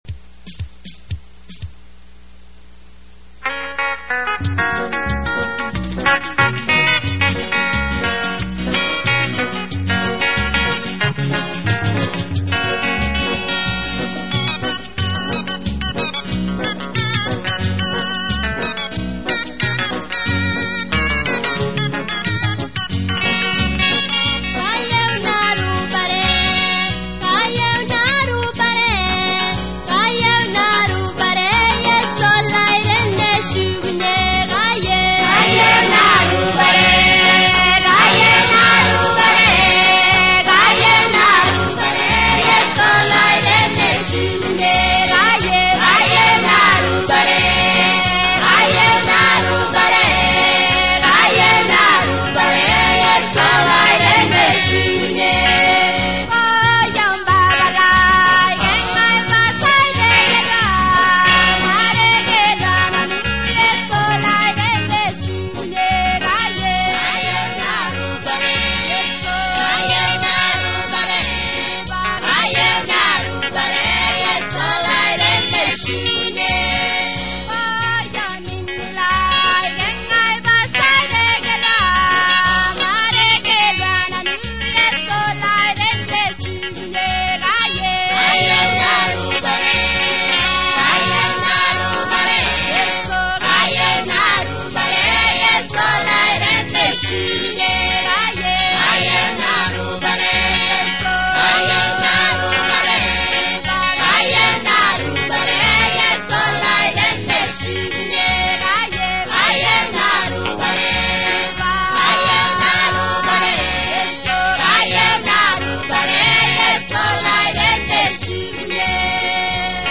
And some Christian songs in Maasai/Samburu.